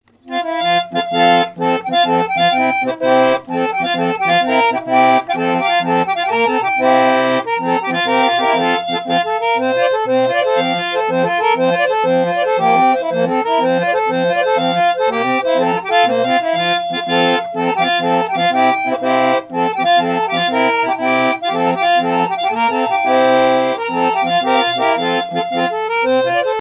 Figure de quadrille sur un orgue de barbarie
danse : quadrille
musique mécanique
Pièce musicale inédite